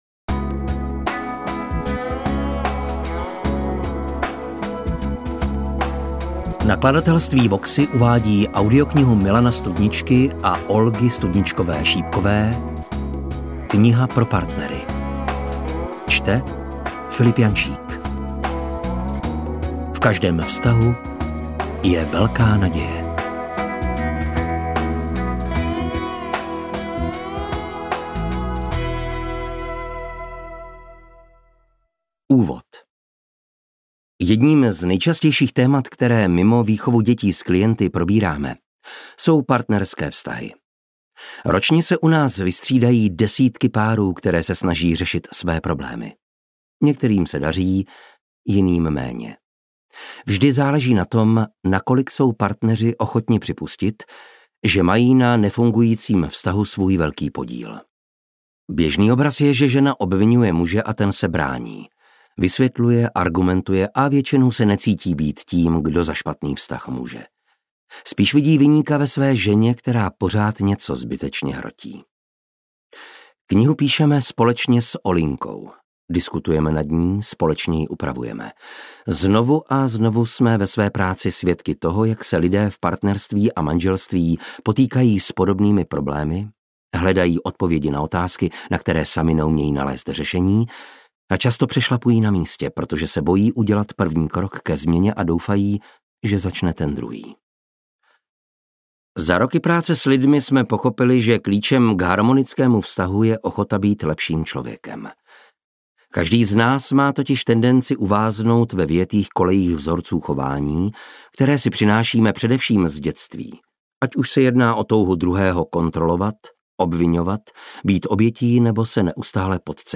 Interpret/Interpretka